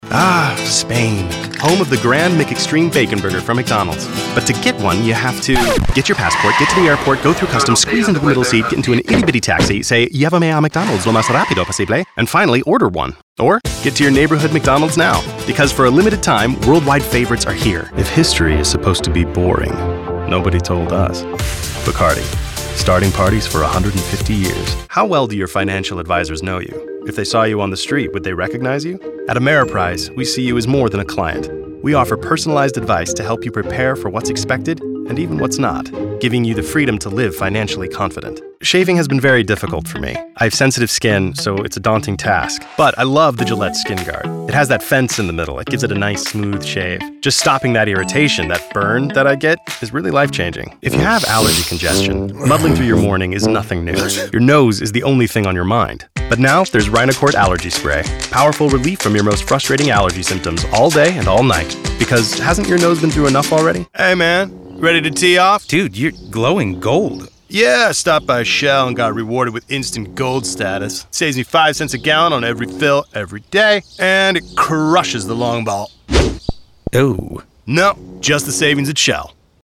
COMMERCIAL V/O DEMO